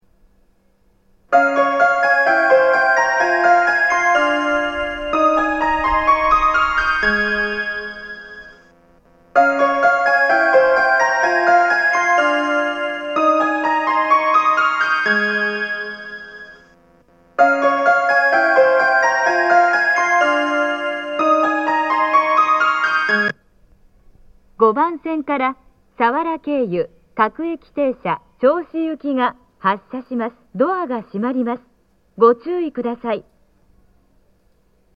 発車メロディー 毎時1本程度使用されるホームです。始発列車であれば2コーラス目には入りやすいです。